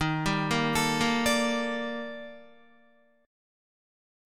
EbM#11 chord